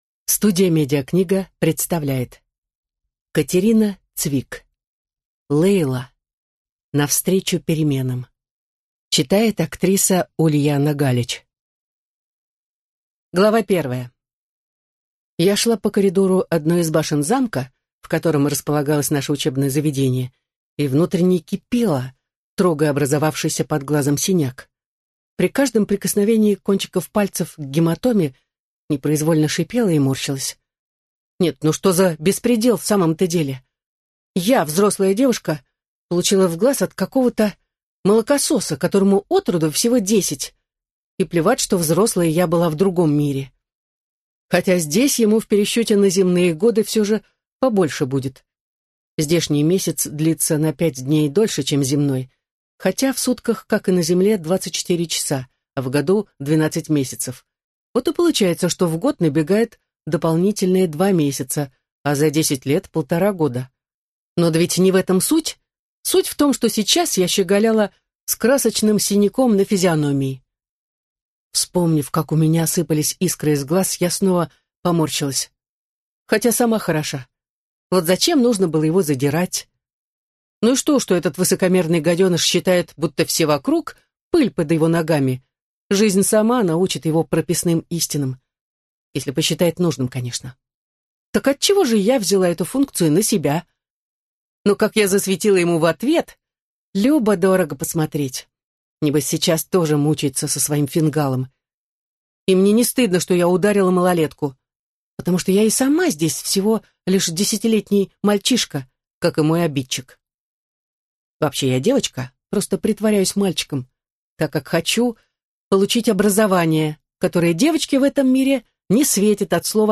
Аудиокнига Лейла. Навстречу переменам | Библиотека аудиокниг